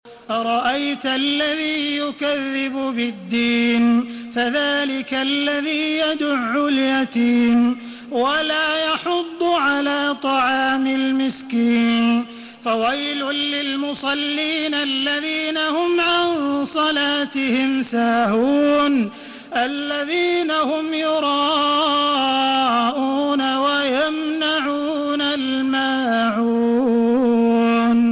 Listen and download the full recitation in MP3 format via direct and fast links in multiple qualities to your mobile phone.
Makkah Taraweeh 1432
Murattal Hafs An Asim